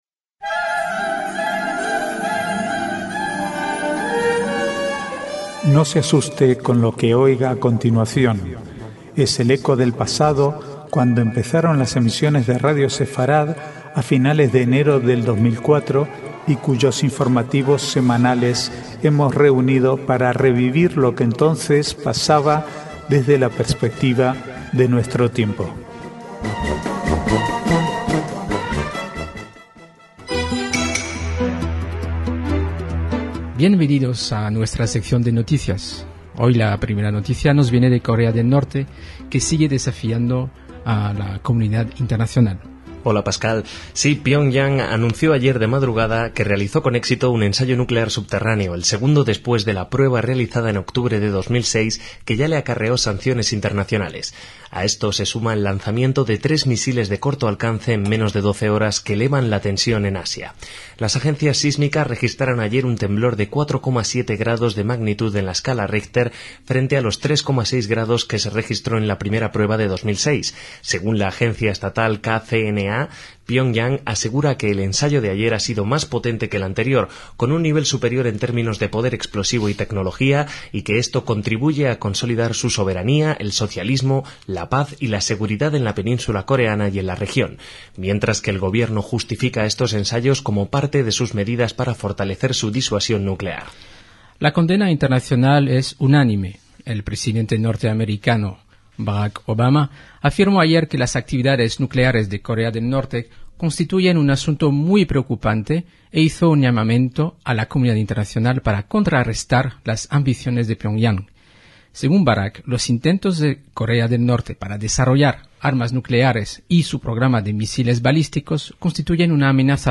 Archivo de noticias del 26 al 28/5/2009